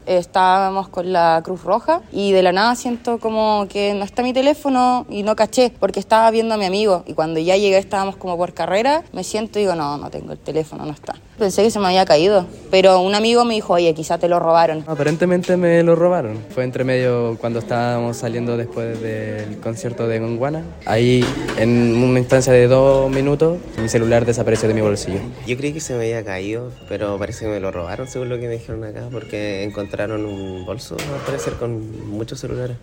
En conversación con Radio Bío Bío, algunas de las víctimas relataron que en cosa de minutos ya no estaban en poder de sus teléfonos.